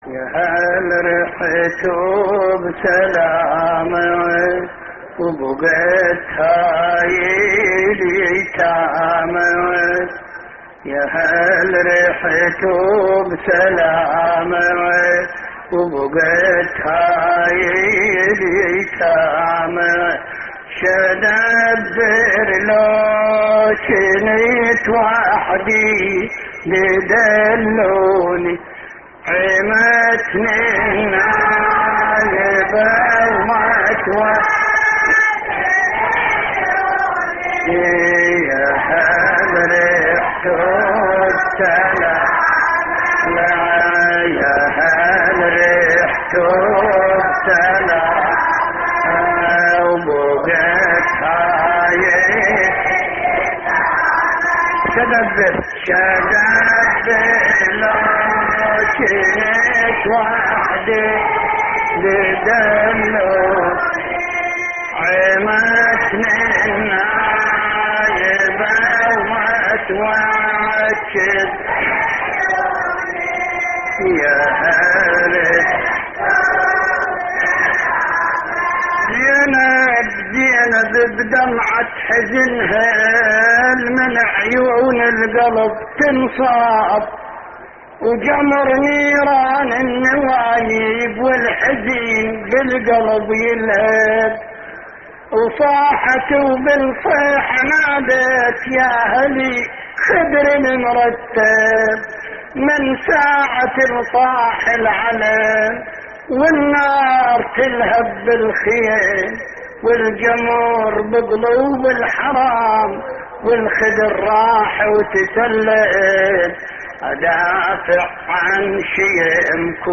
تحميل : يهالرحتوا بسلامة وبُقت هاي اليتامى / الرادود حمزة الصغير / اللطميات الحسينية / موقع يا حسين